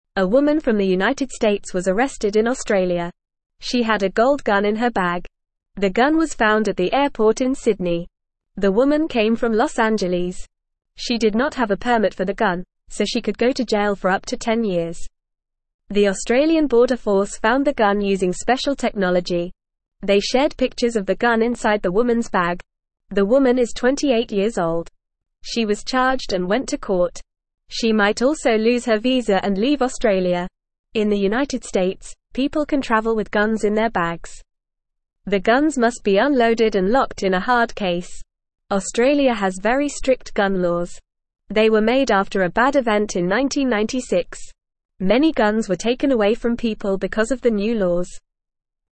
Fast
English-Newsroom-Beginner-FAST-Reading-Woman-Arrested-in-Australia-with-Gold-Gun.mp3